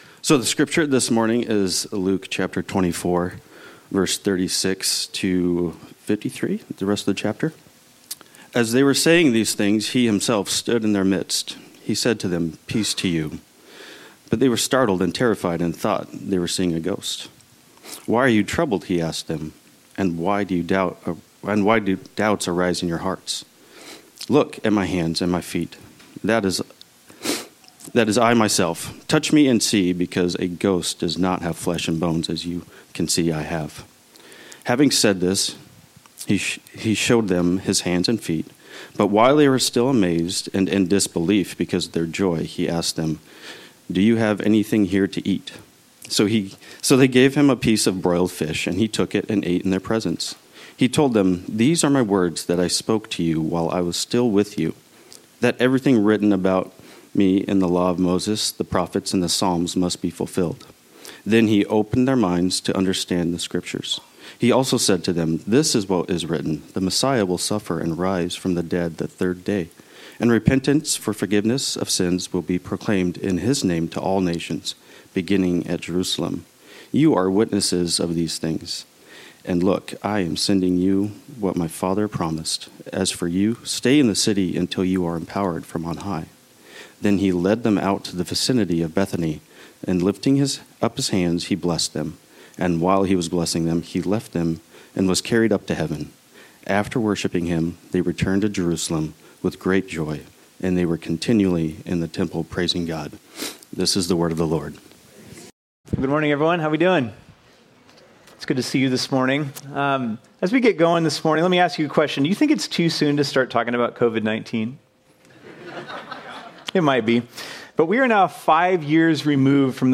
This sermon was originally preached on Sunday, April 27, 2025.